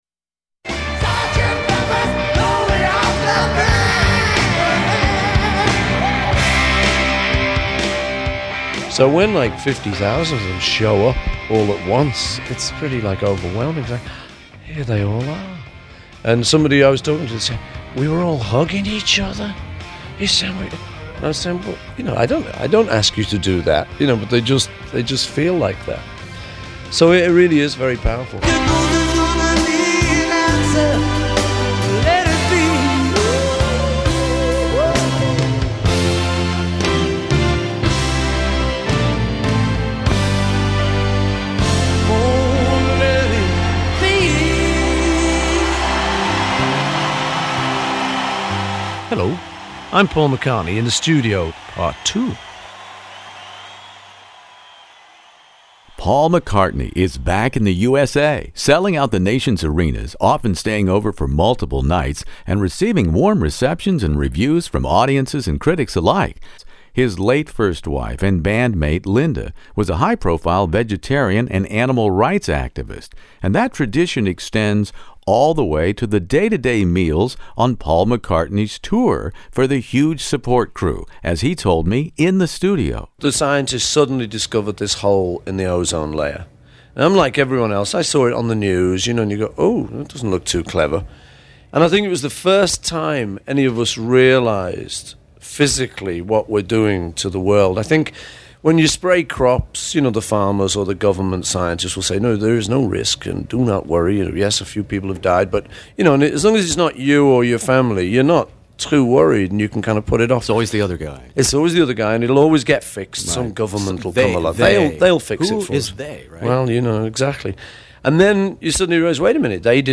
Paul McCartney interview about live "Back in the US" pt 2
The conclusion in this classic rock interview.